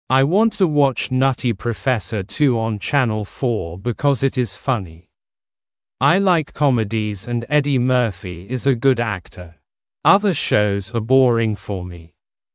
Произношение:
Ай уонт ту уотч Нати Професэ ту он Чэннэл фо: бикоз ит из фани. Ай лайк комидиз энд Эди Мёрфи из э гуд актэ. Азэ шоуз а боринг фо ми.